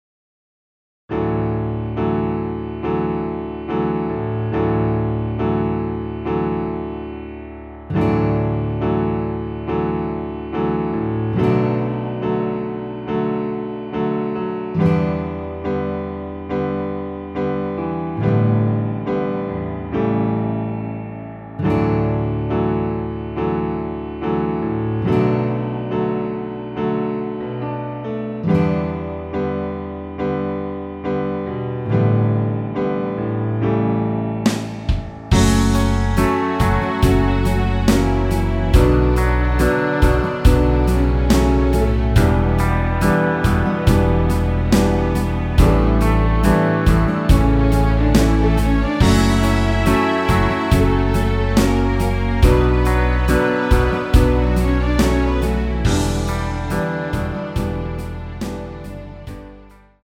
원키에서(-5)내린 MR입니다.
엔딩이 너무길고페이드 아웃이라 라이브 하시기 좋게 4마디로 편곡 하였습니다.
앞부분30초, 뒷부분30초씩 편집해서 올려 드리고 있습니다.
중간에 음이 끈어지고 다시 나오는 이유는